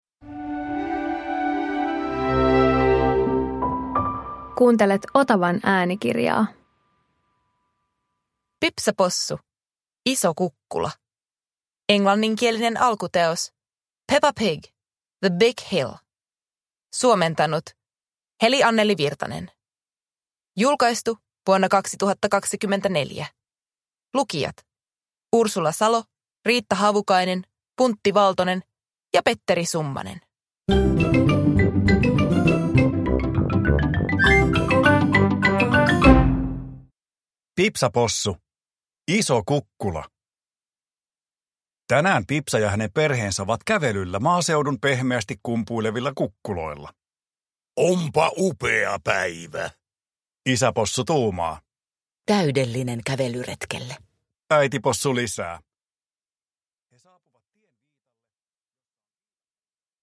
Pipsa Possu - Iso kukkula – Ljudbok